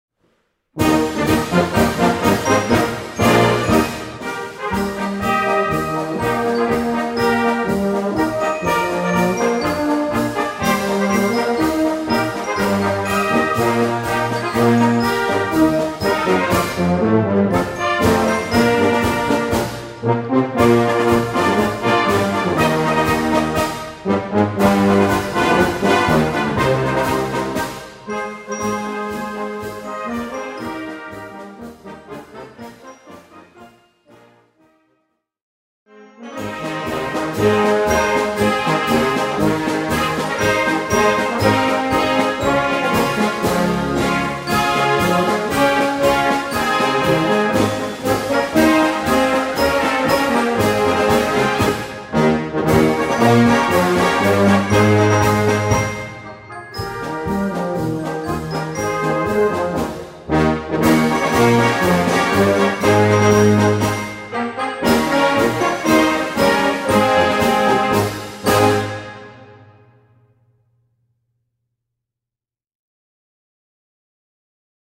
Gattung: Konzertant
2:50 Minuten Besetzung: Blasorchester PDF